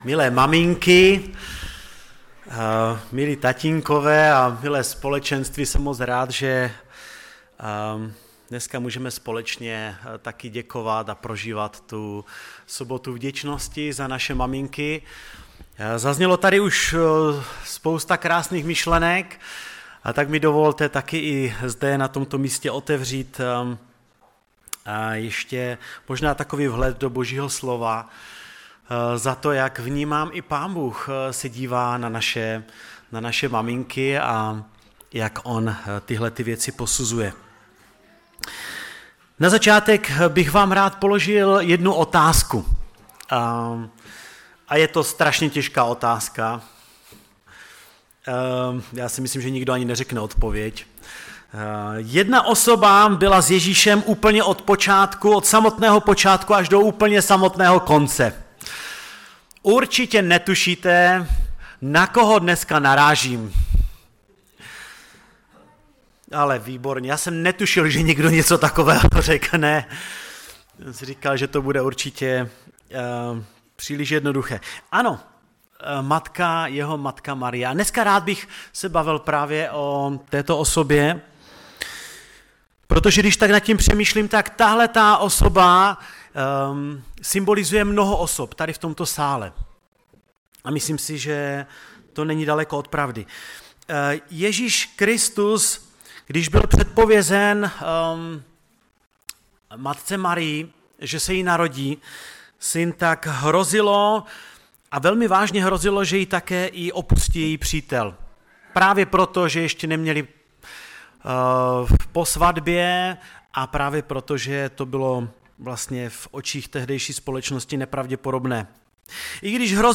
ve sboře Ostrava-Radvanice při bohoslužbě ke Dni matek.
Kázání